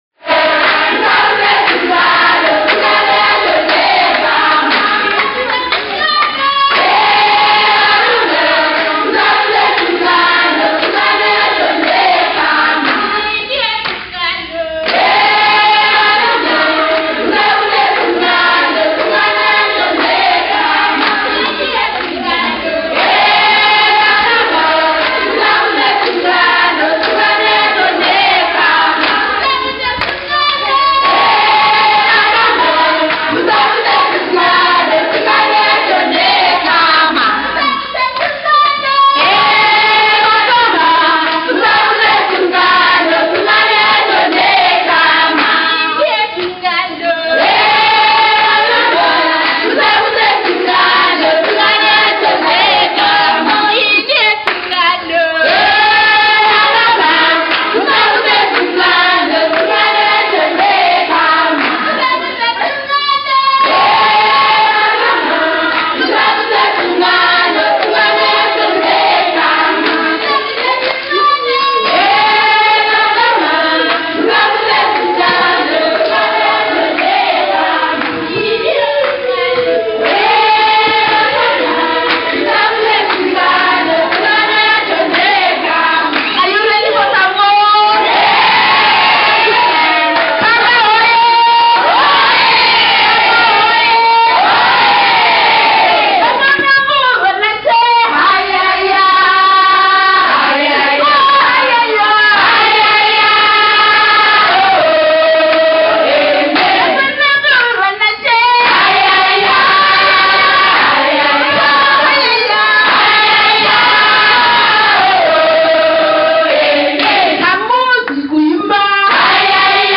Their songs traveled from somber to soaring, taking good time between points on an ethereal journey.
And they are singing…